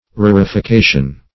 rarification.mp3